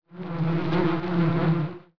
flies2.wav